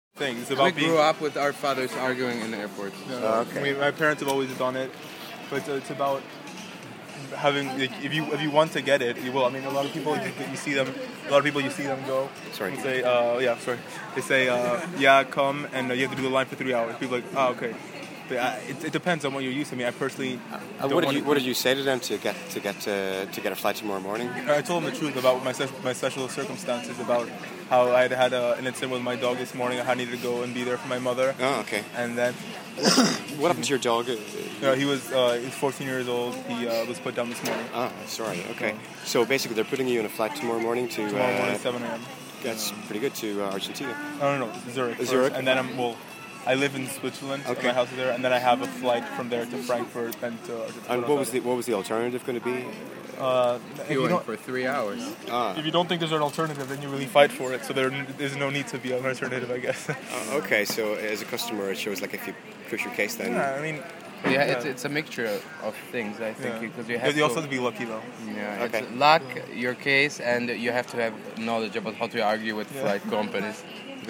A young Argentinian at Heathrow explains how he avoided a 3hr queue by arguing with airline staff and pleading special circumstances (his dog was put down this morning)